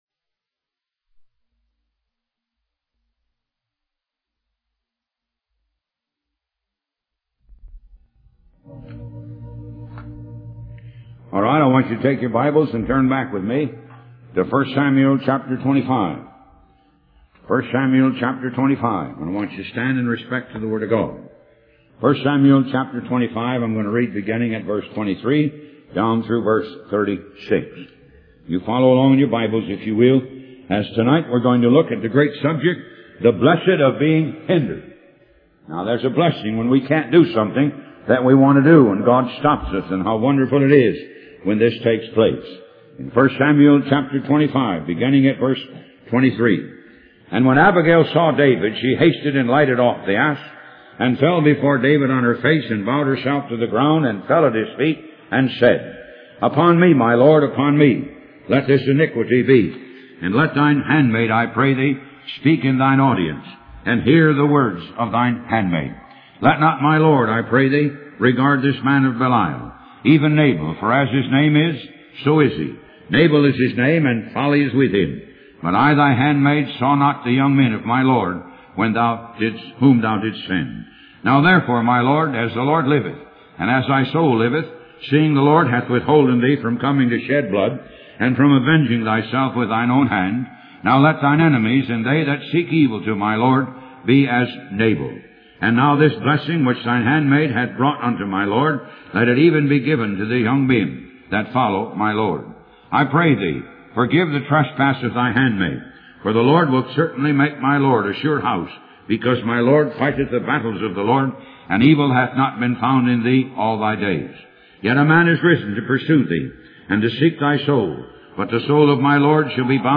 Talk Show Episode, Audio Podcast, Moga - Mercies Of God Association and The Blessing Of Being Hindered on , show guests , about The Blessing Of Being Hindered, categorized as Health & Lifestyle,History,Love & Relationships,Philosophy,Psychology,Christianity,Inspirational,Motivational,Society and Culture